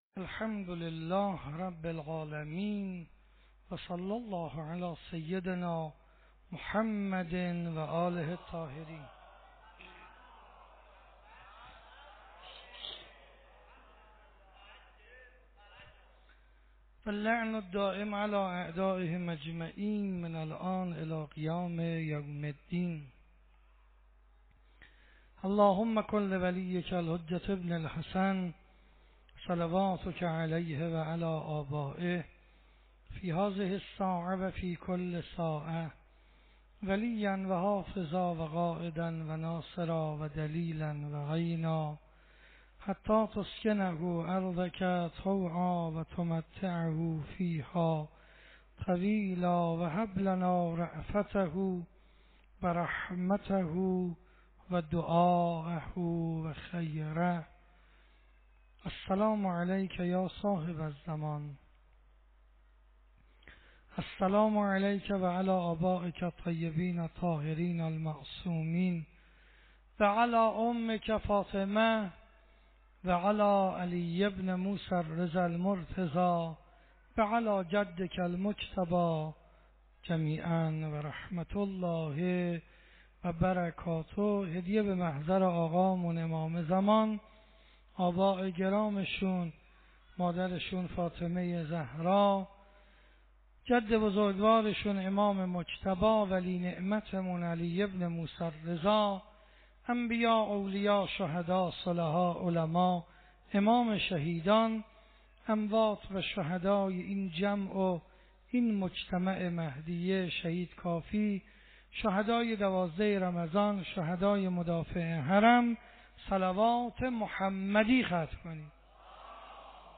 شب 16 ماه مبارک رمضان 96 - مهدیه تهران
سخنرانی